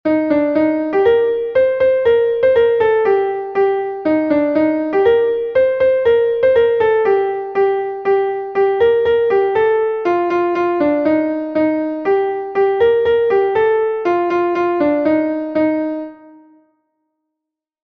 Gavotenn Bubri Pleurdud est un Gavotte de Bretagne